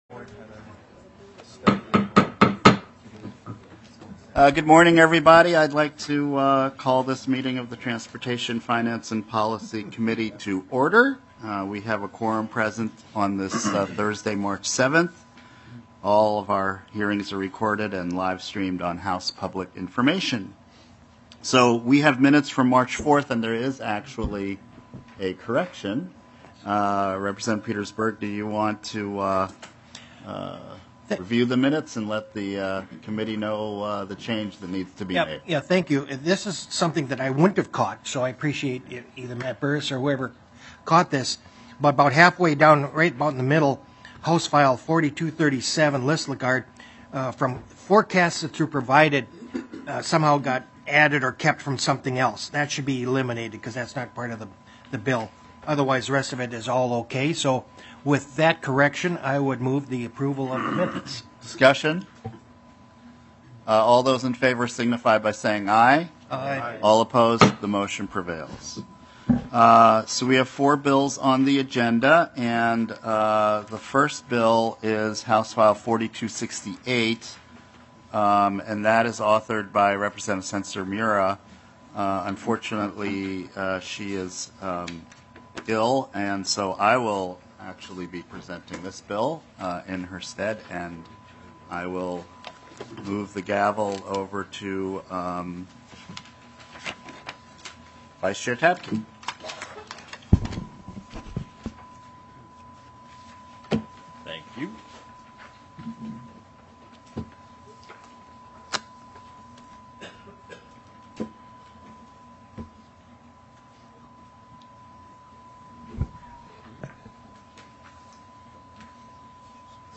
Meeting Details - Thursday, February 29, 2024, 1:00 PM